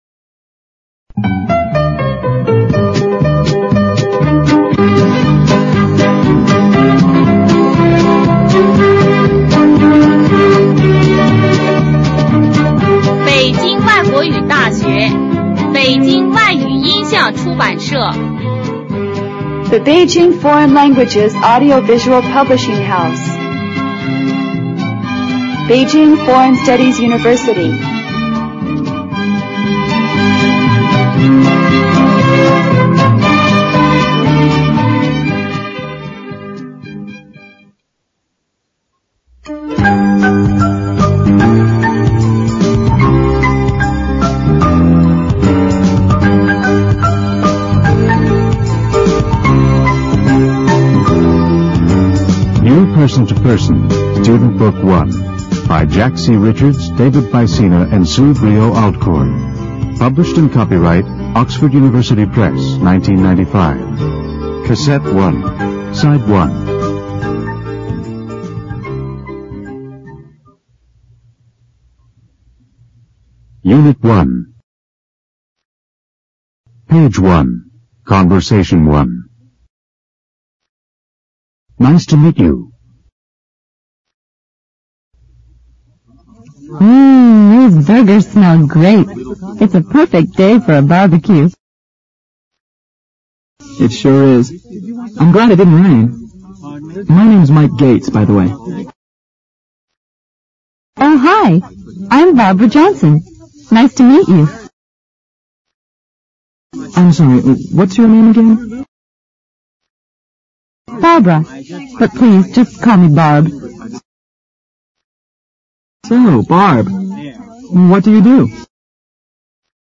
简单英语口语对话 unit1_conbersation1_new(mp3+lrc字幕)